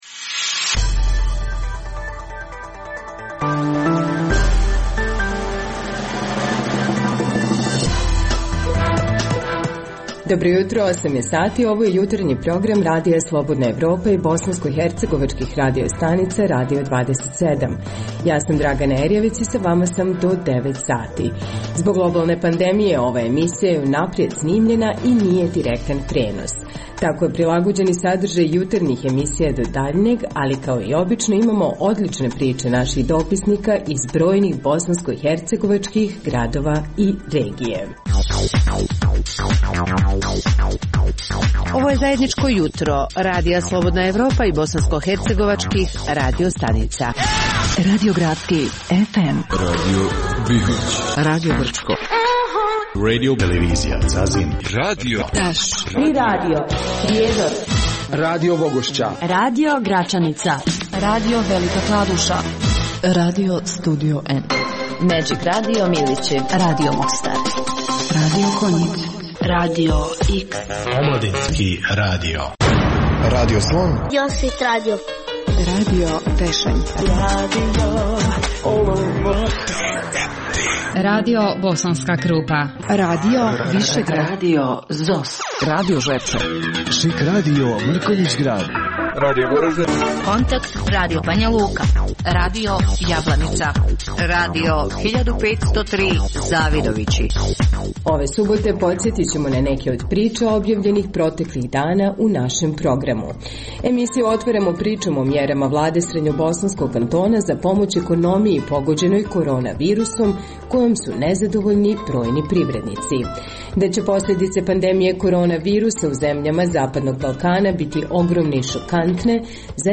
Zbog pooštrenih mjera kretanja u cilju sprječavanja zaraze korona virusom, ovaj program je unaprijed snimljen. Poslušajte neke od priča iz raznih krajeva Bosne i Hercegovine.